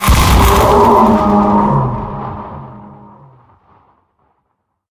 48d440e14c Divergent / mods / Soundscape Overhaul / gamedata / sounds / monsters / poltergeist / tele_death_0.ogg 40 KiB (Stored with Git LFS) Raw History